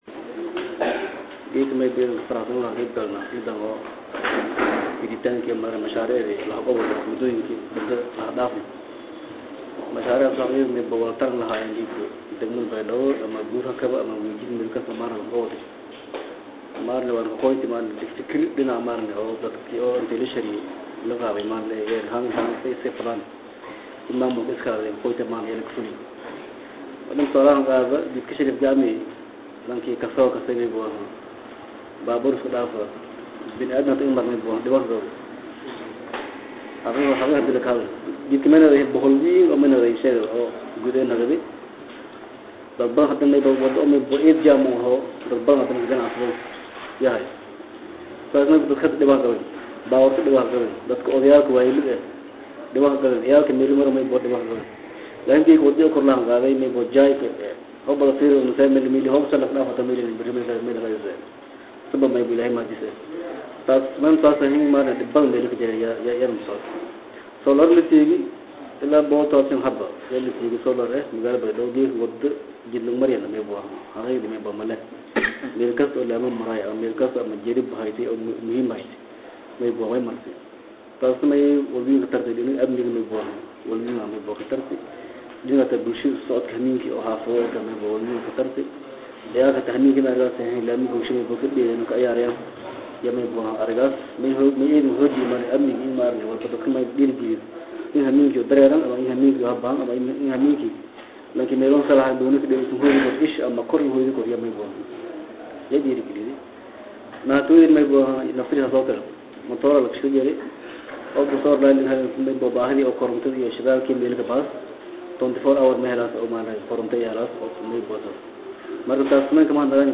Baydhabo(INO)- Xaflad si heer sare ah loosoo agaasimay lagunsoo xirayay mashaariic dhowaanahanba ay dowlada UK ka waday gobolada Koonfur Galbeed ayaa maanta waxaa lagu qabtay xarunta gobolka Bay ee Baydhaba.